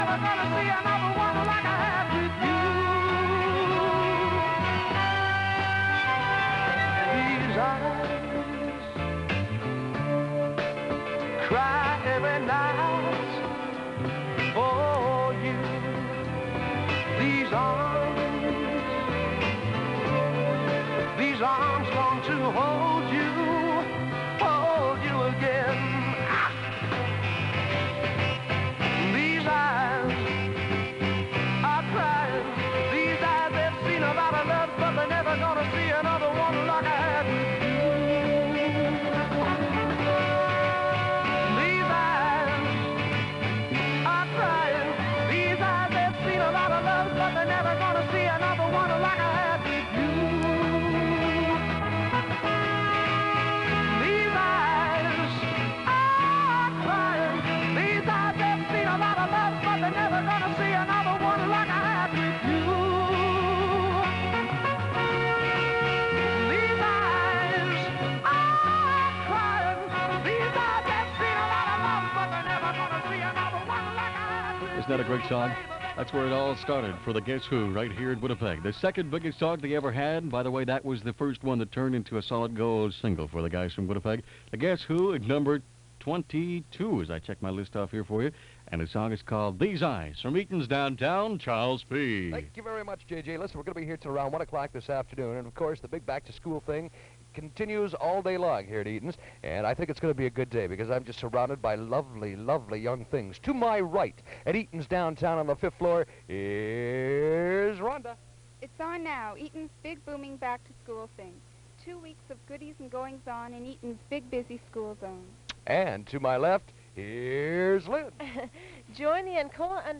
Winnipeg Radio in 1971